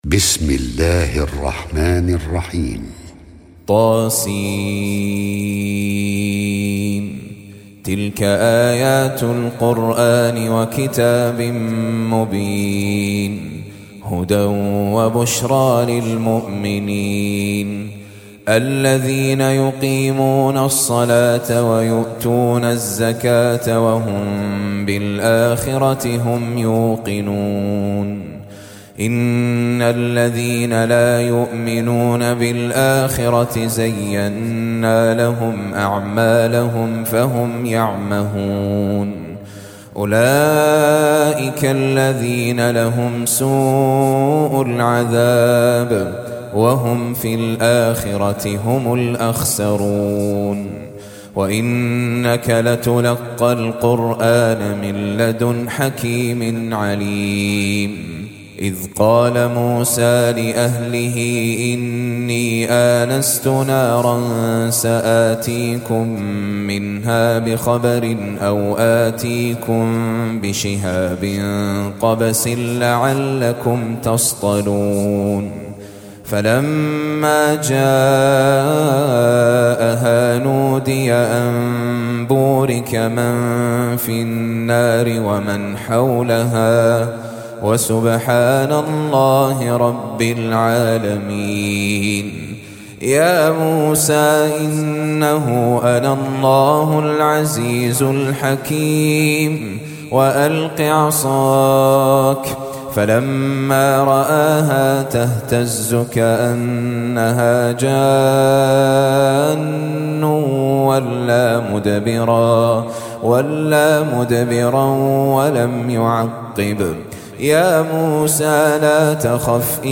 Surah Sequence تتابع السورة Download Surah حمّل السورة Reciting Murattalah Audio for 27. Surah An-Naml سورة النّمل N.B *Surah Includes Al-Basmalah Reciters Sequents تتابع التلاوات Reciters Repeats تكرار التلاوات